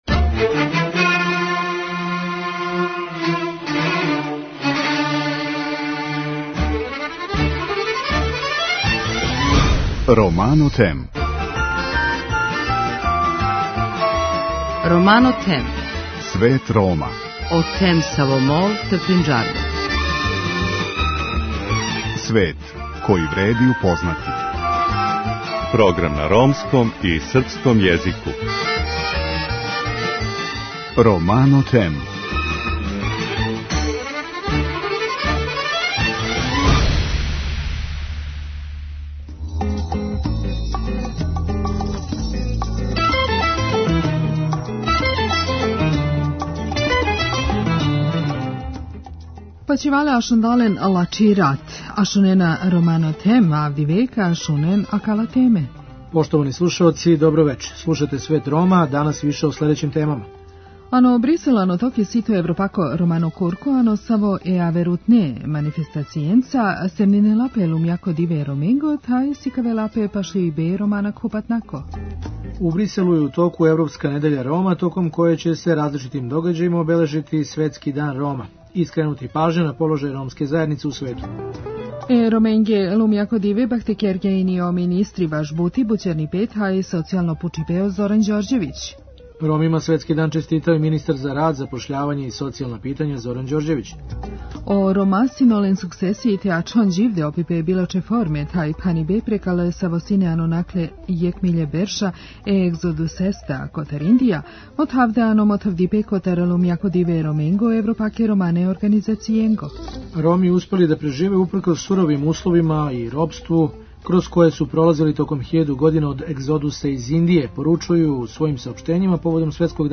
Преносимо извештај из Брисела где је у току Европска недеља Рома. Шта за ромску заједницу значи убедљива победа Орбановог Фидеса на јуче завршеним парламентарним изборима у Мађарској? - одговор даје Гидеон Рахман коментатор Фајненшл Тајмса.